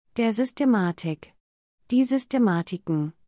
/zʏstemaˈtiːk/ · /zʏstemaˈtiːk/ · /zʏstemaˈtiːkən/